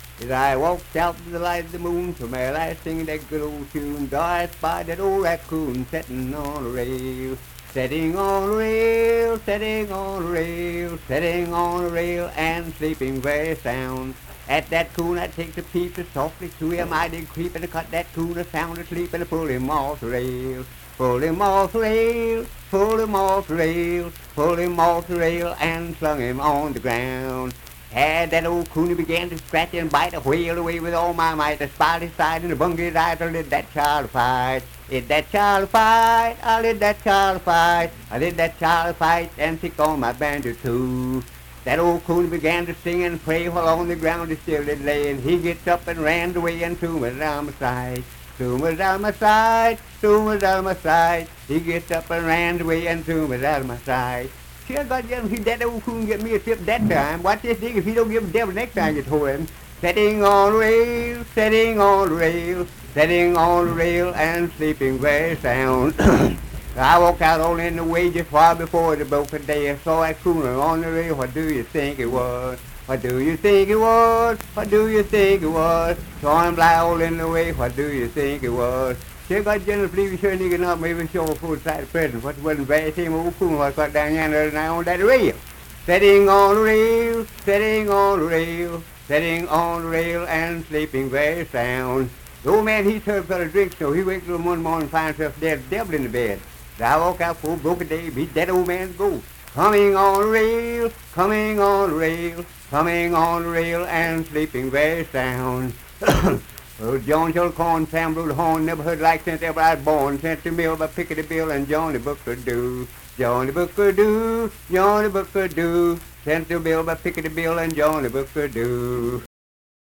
Unaccompanied vocal and banjo music
Minstrel, Blackface, and African-American Songs
Voice (sung)
Clay (W. Va.), Clay County (W. Va.)